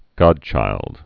(gŏdchīld)